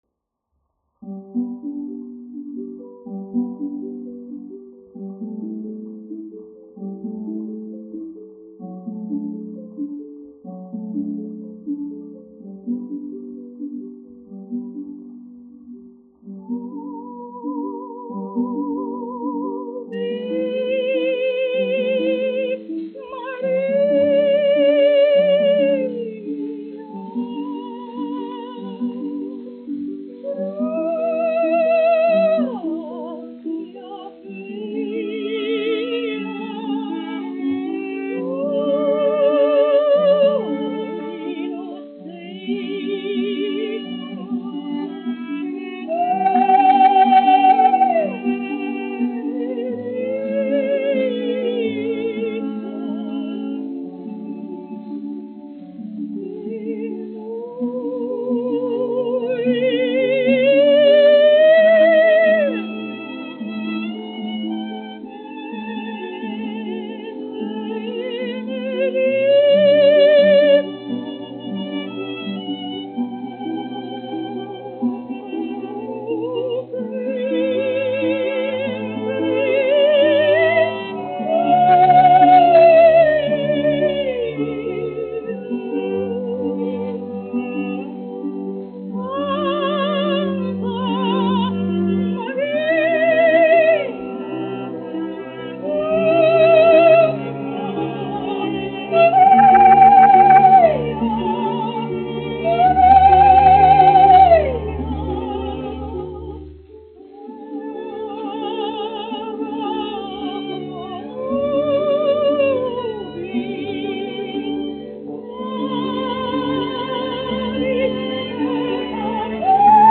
1 skpl. : analogs, 78 apgr/min, mono ; 25 cm
Dziesmas (augsta balss)
Garīgās dziesmas
Latvijas vēsturiskie šellaka skaņuplašu ieraksti (Kolekcija)